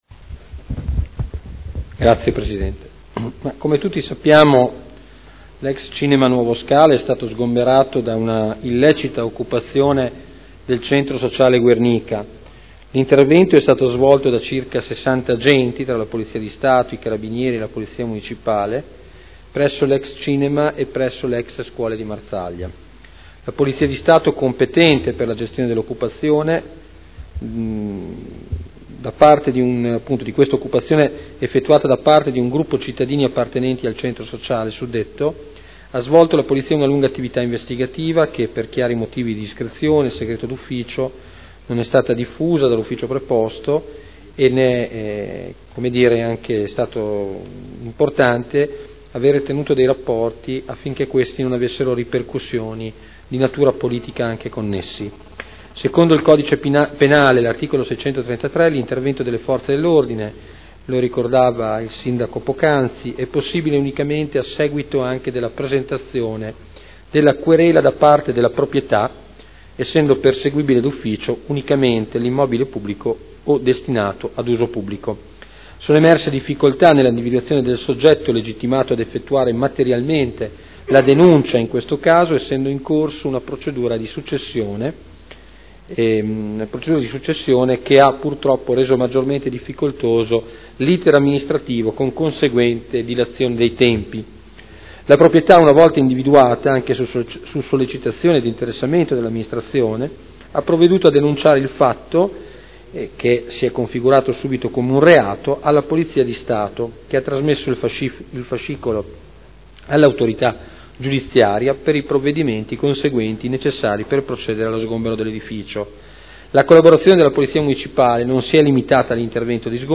Antonino Marino — Sito Audio Consiglio Comunale